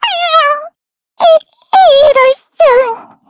Taily-Vox_Dead_kr.wav